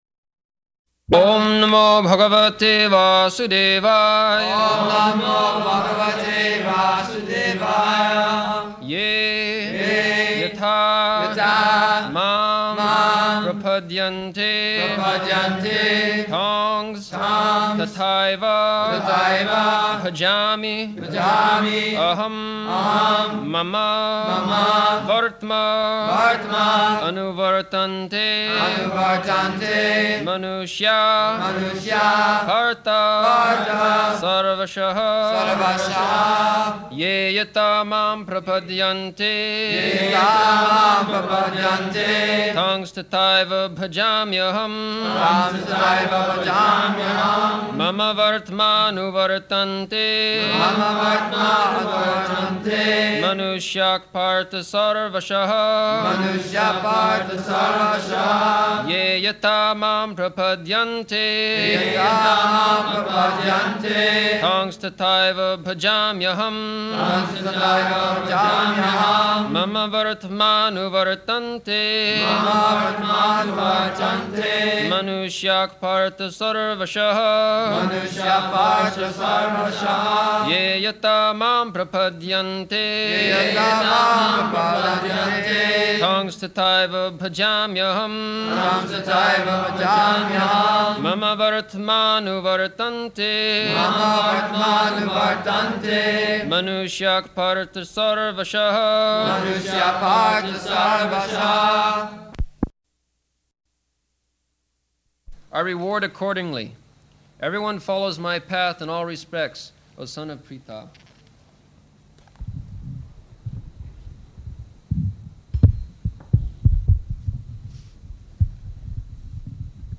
August 3rd 1974 Location: Vṛndāvana Audio file
[devotees repeat] [leads chanting of verse, etc.]